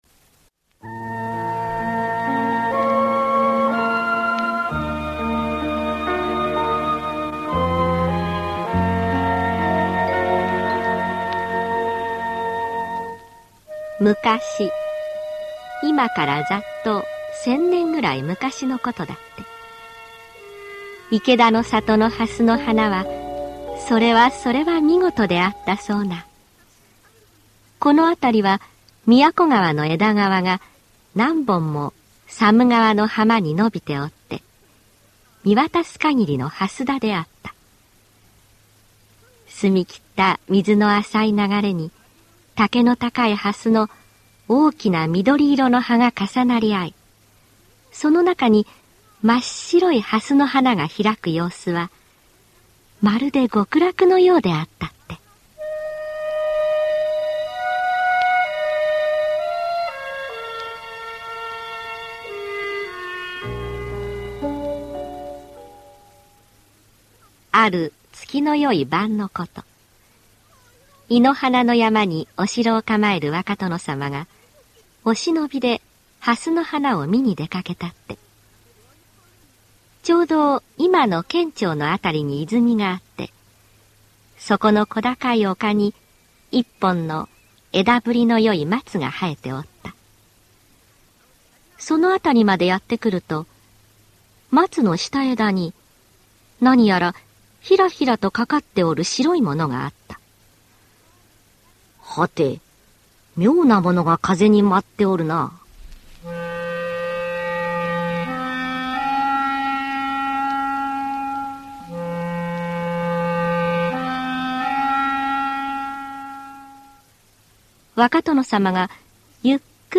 [オーディオブック] はごろも